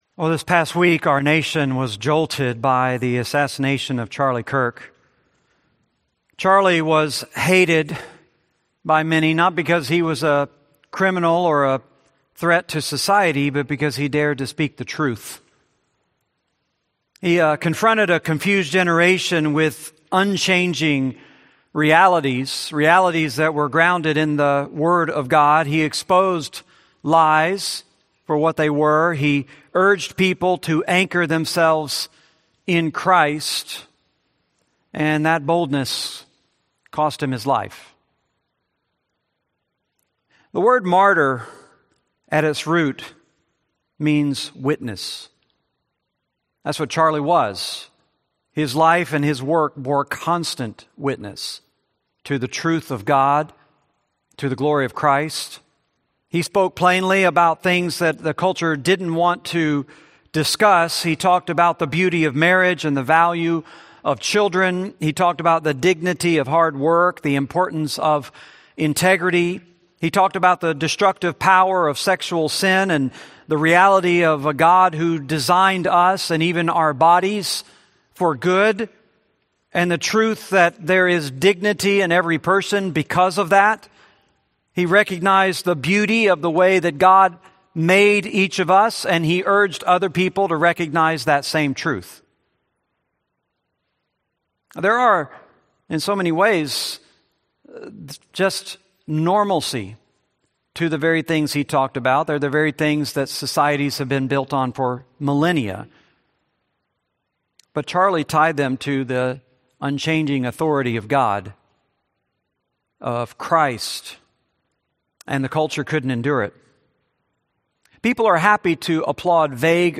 Series: 1 Peter, Sunday Sermons